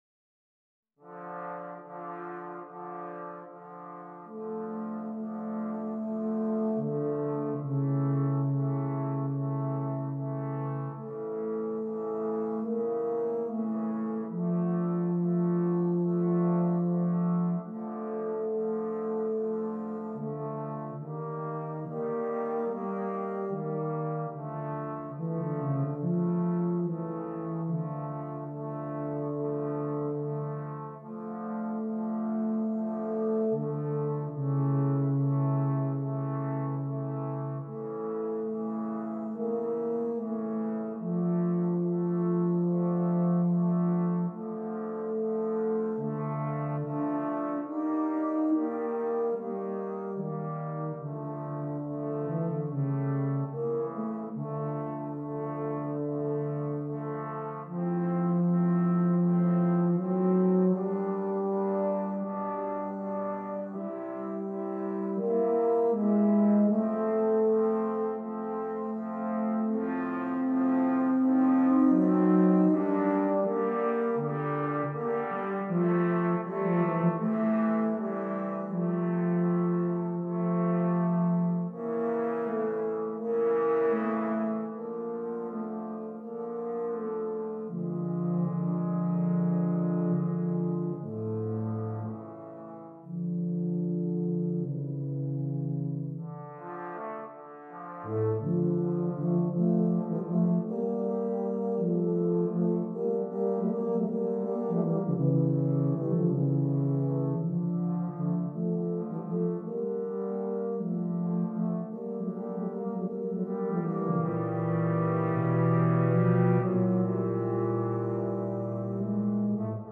Besetzung: Tuba Quartet